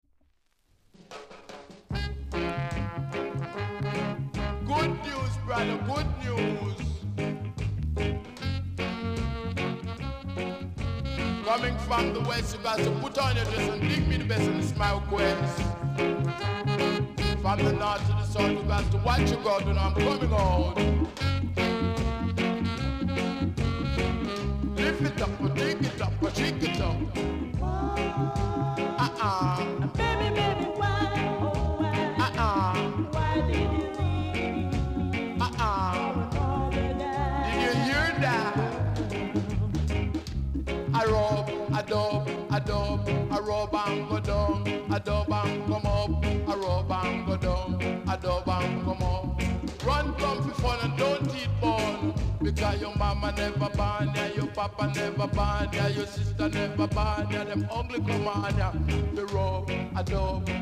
※多少小さなノイズはありますが概ね良好です。
NICE DEEJAY!!